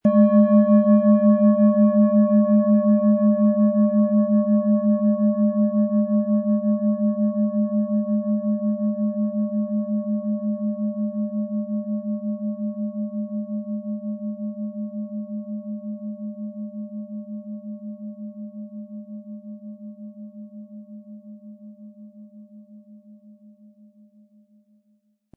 Tibetische Fuss-Herz- und Bauch-Klangschale, Ø 17,8 cm, 800-900 Gramm, mit Klöppel
Im Preis enthalten ist ein passender Klöppel zur Klangschale, der die Schwingung der Schale gut zur Geltung bringt.
HerstellungIn Handarbeit getrieben
MaterialBronze